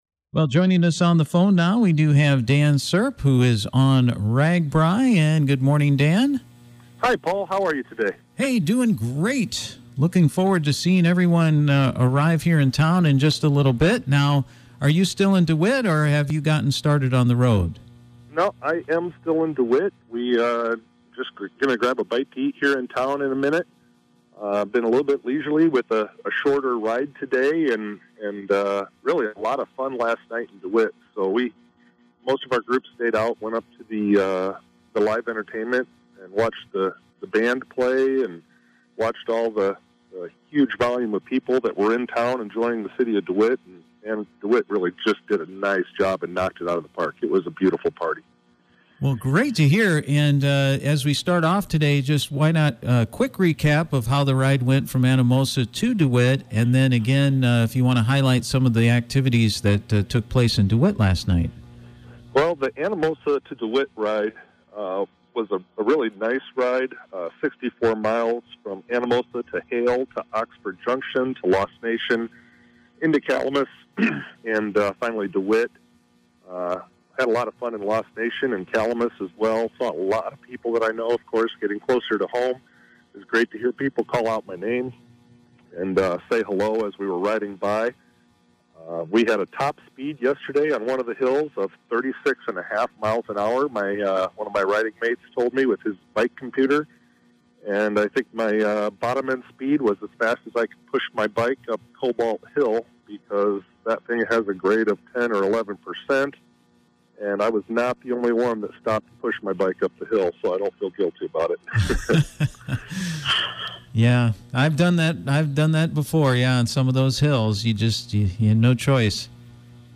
Clinton County Supervisor Dan Srp is on the Registers Annual Great Bike Ride Across Iowa for the second time and is providing updates all week at 7:50 am on KROS.